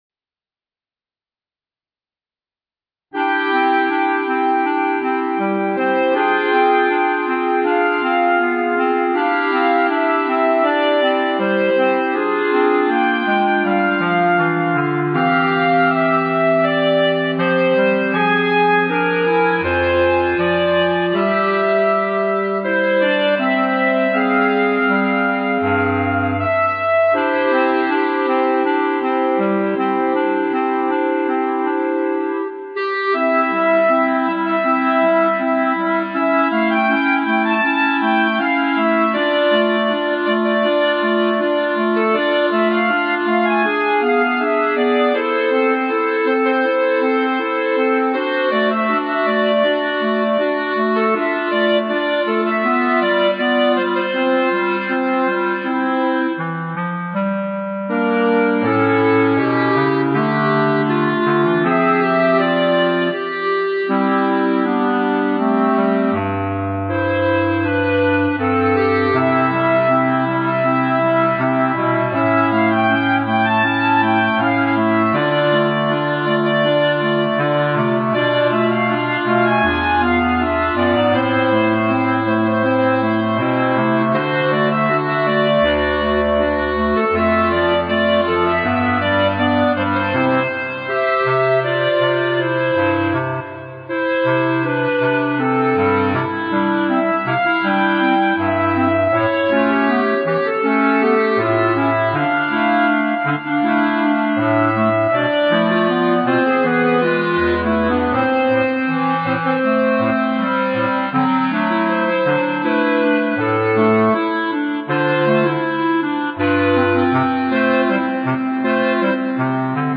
B♭ Clarinet 1 B♭ Clarinet 2 B♭ Clarinet 3 Bass Clarinet
单簧管四重奏
风格： 流行